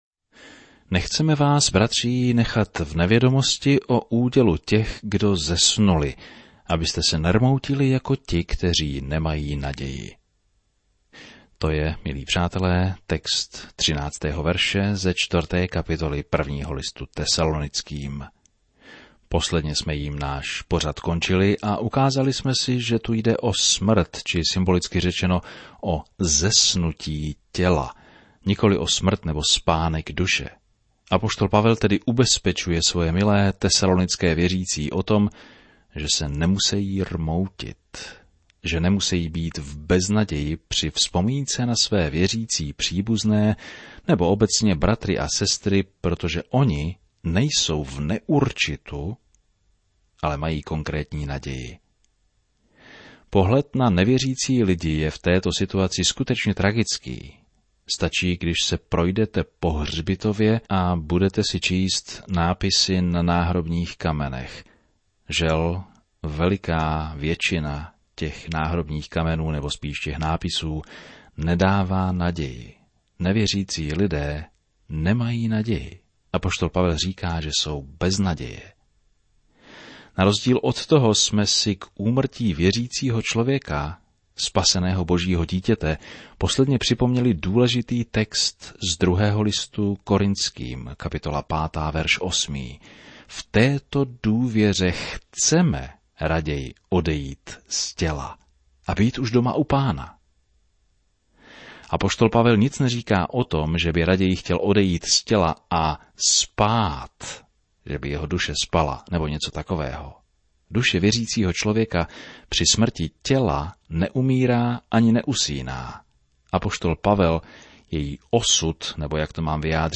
Je zamýšlen jako každodenní 30ti minutový rozhlasový pořad, který systematicky provádí posluchače celou Biblí.